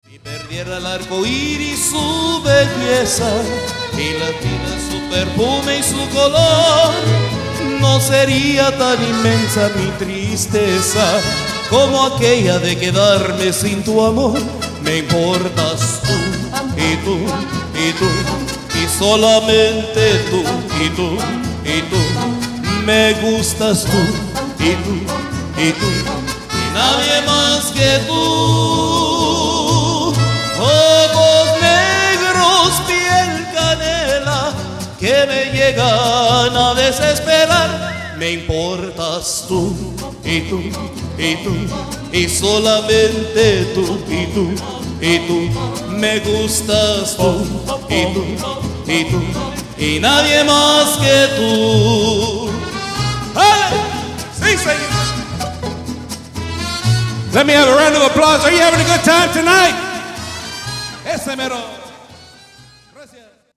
To this day, this group continues to be the leader for high quality Mariachi music in the Dallas, Fort Worth metroplex.